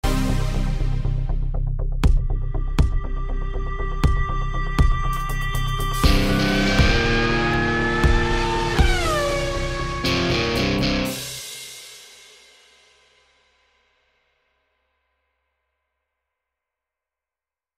Création originale musique à l'image + sound design/bruitage